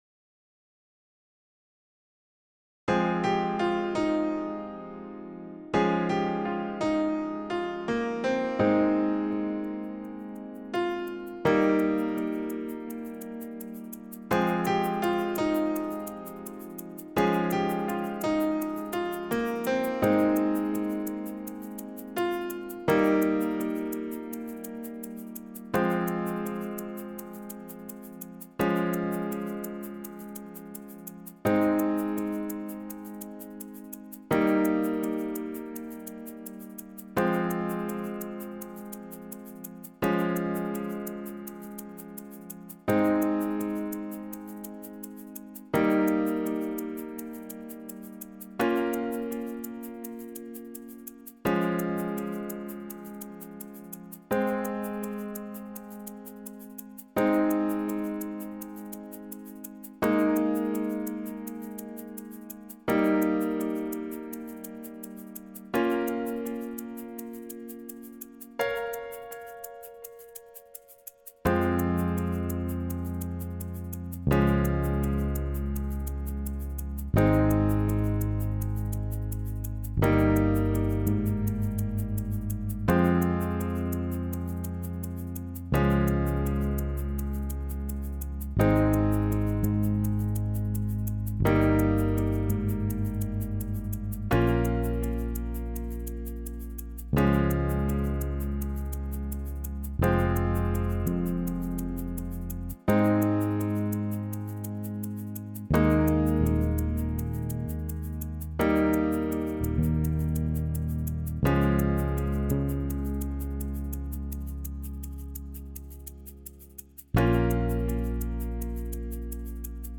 Yahweh Bass Drums loop Piano.m4a